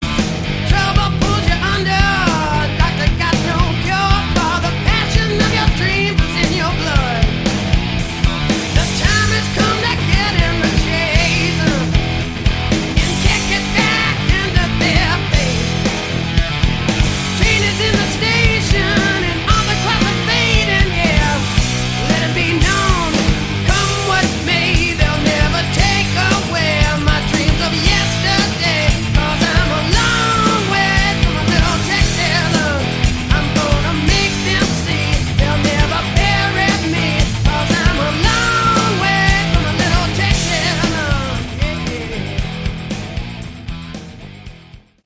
Category: Hard Rock
Lead Vocals
Drums
Lead Guitar
Bass
Backing Vocals
Great bluesy hard rock record
This is very good blues hard rock.